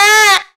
SHORT SAX.wav